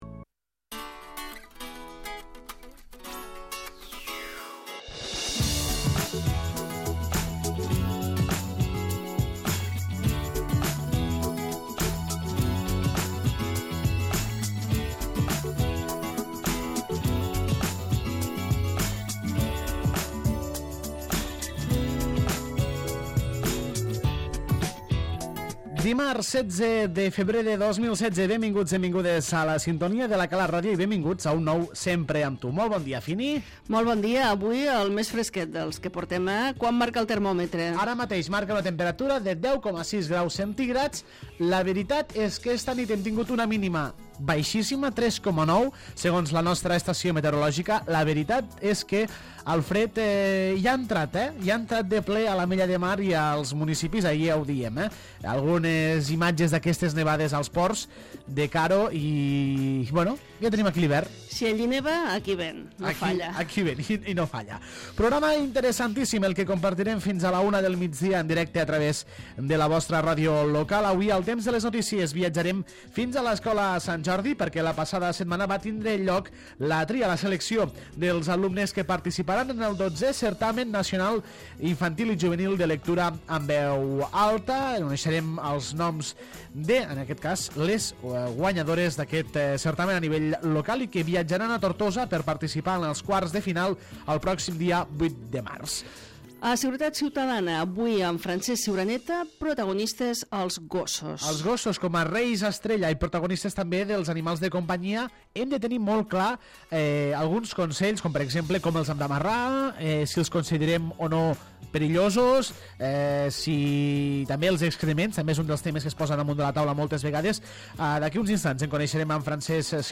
Nova edició del magazín dels migdies de La Cala Ràdio, corresponent al dimarts 16 de febrer.